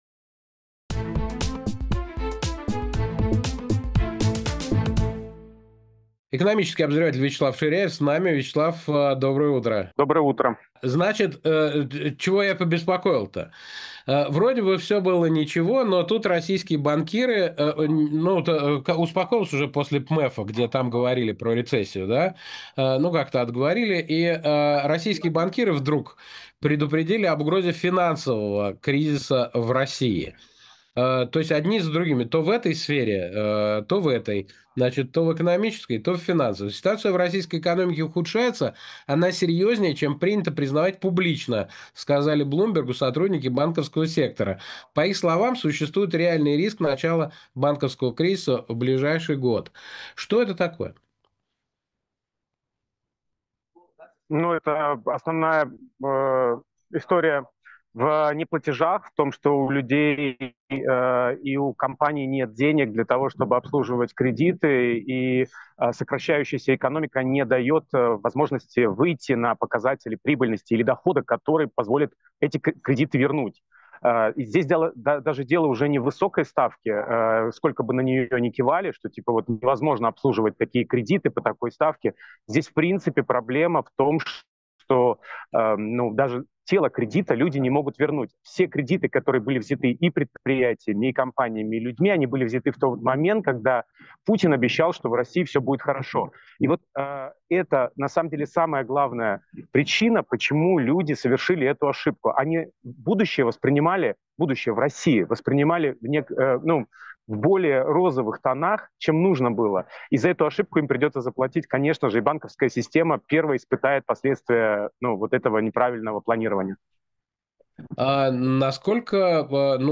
Фрагмент эфира от 27.06.25
«Плановое охлаждение» или явный кризис? Что сейчас происходит с экономикой Александр Плющев журналист